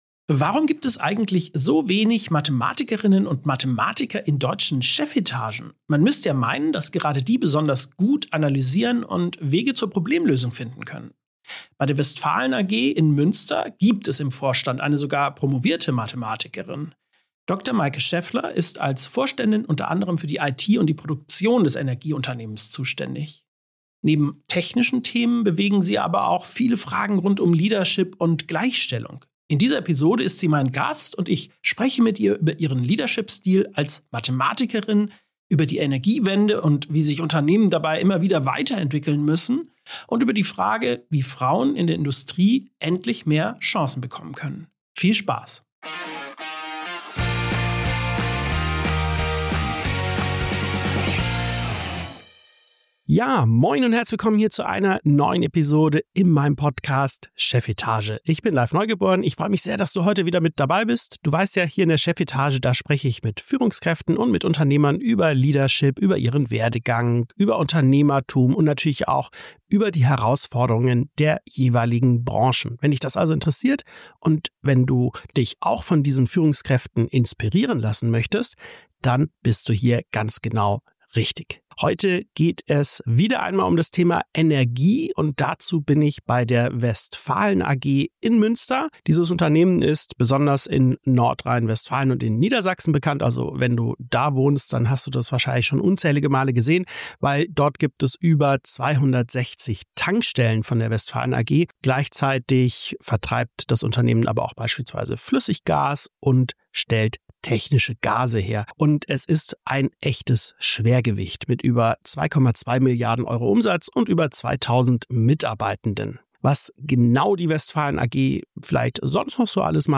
74 ~ Chefetage - CEOs, Unternehmer und Führungskräfte im Gespräch Podcast